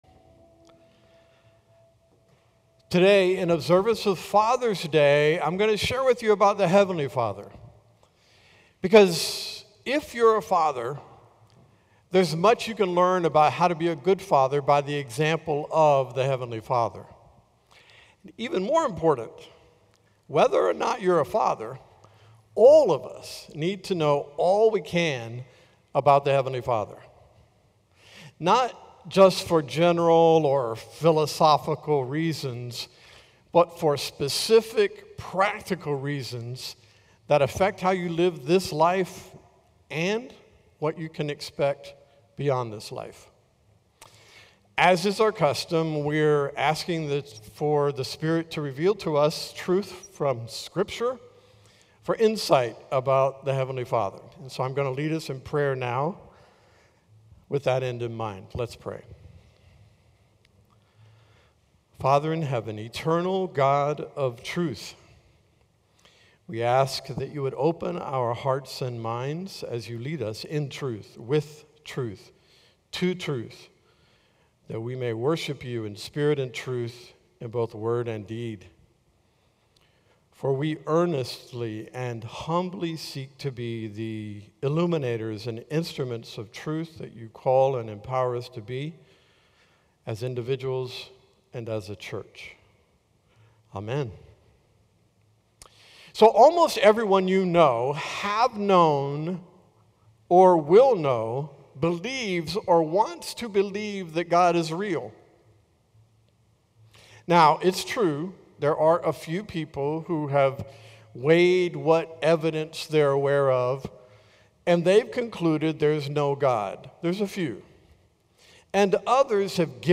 Holiday Messages – Calvary El Calvario